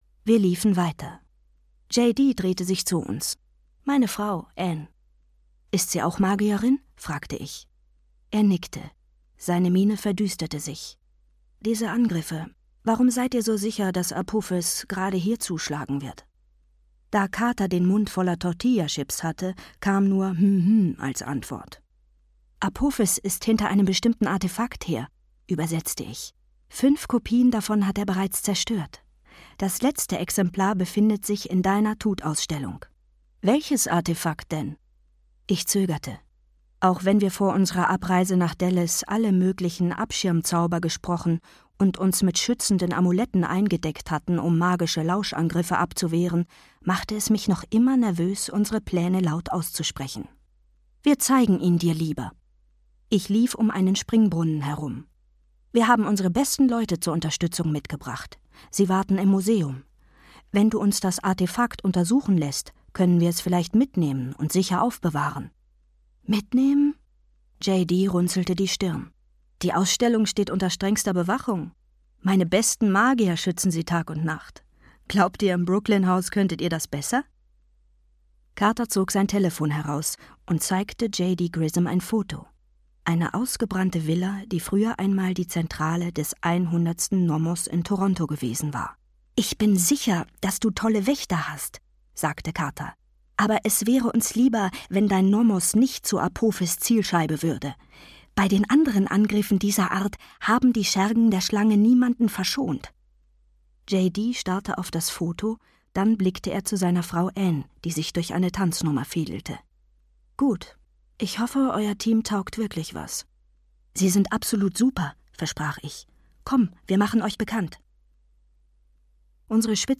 Die Kane-Chroniken 3: Der Schatten der Schlange - Rick Riordan - Hörbuch